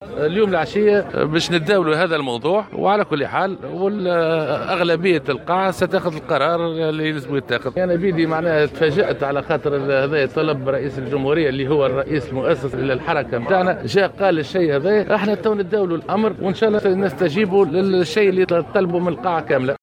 وقال حافظ قائد السبسي في تصريح لمراسلة الجوهرة اف ام، إنه سيتم مساء اليوم السبت، في أشغال المؤتمر الانتخابي الأول للنداء، تداول المسألة واتخاذ القرار بالأغلبية.